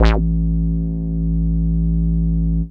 bseTTE48018moog-A.wav